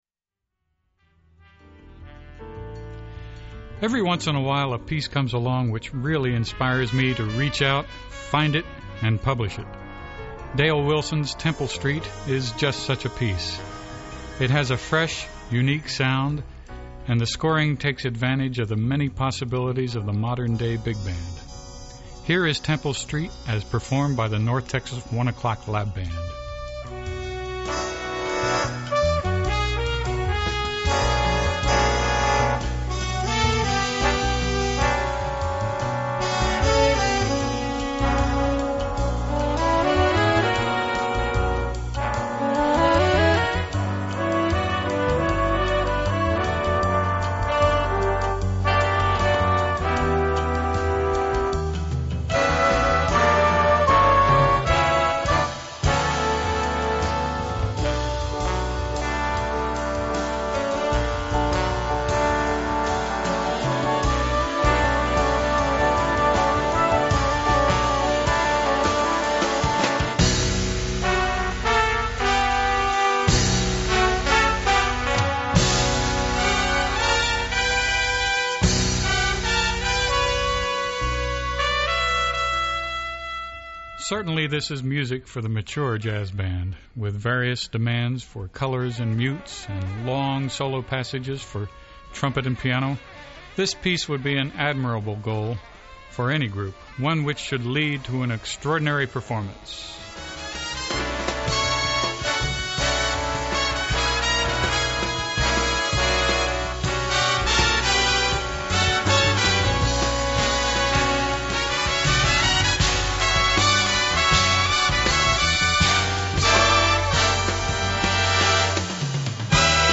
Jazz Classics and New Composers Series
(10 brass) Full Score (on Demo CD 104)